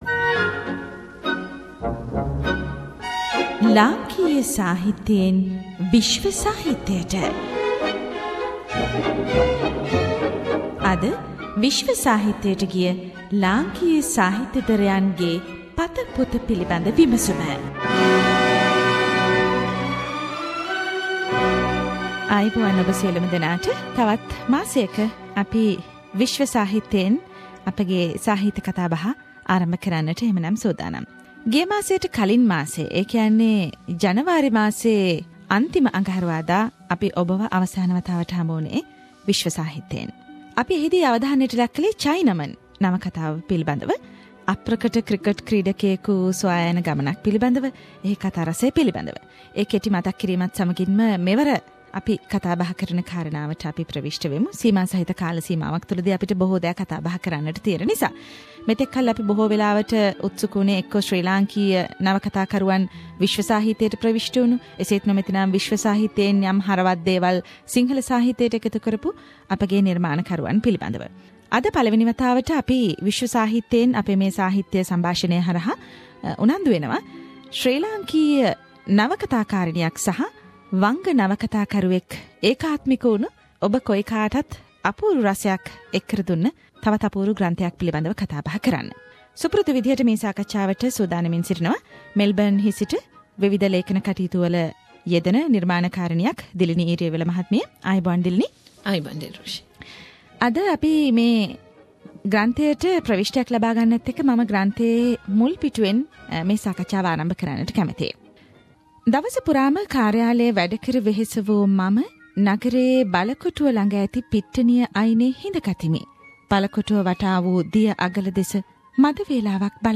“SBS Sinhala” monthly discussion forum of world literature – “Aranyak” – Aranakata Pem Banda